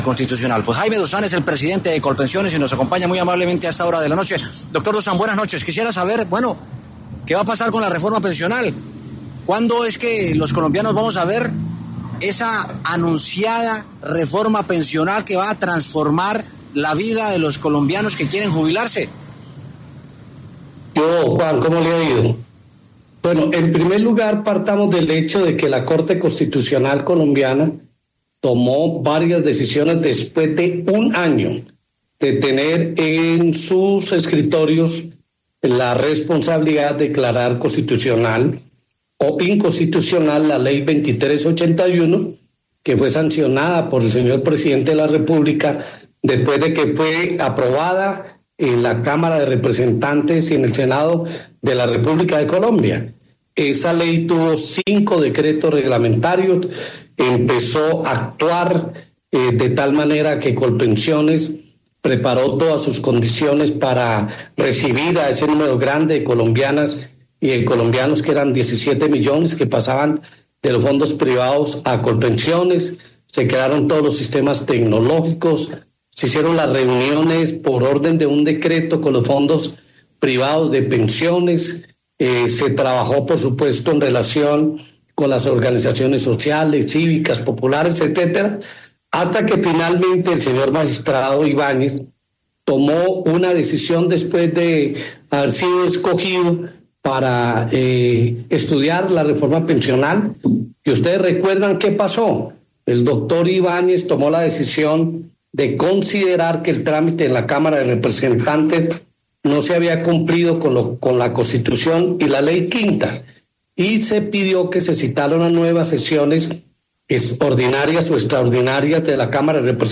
aime Dussan, presidente de Colpensiones, pasó por los micrófonos de W Sin Carreta y dio detalles de la situación del proyecto de la reforma pensional.